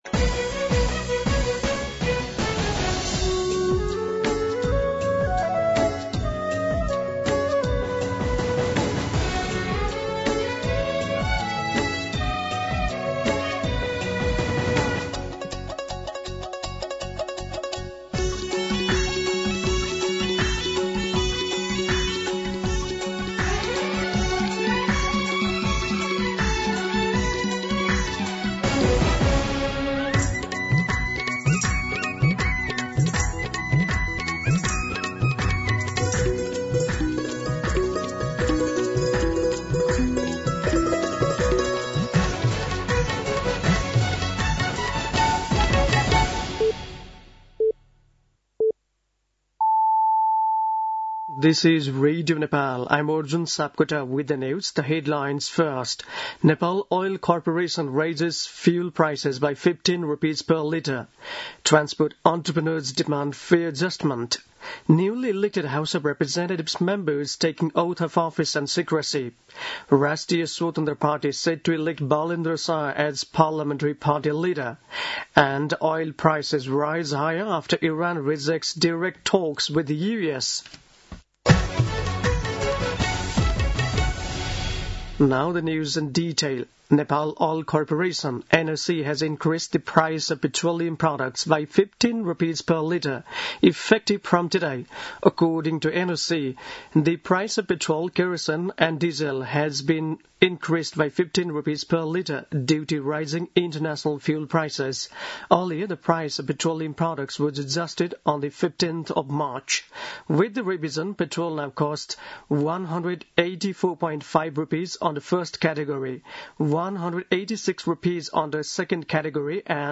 An online outlet of Nepal's national radio broadcaster
दिउँसो २ बजेको अङ्ग्रेजी समाचार : १२ चैत , २०८२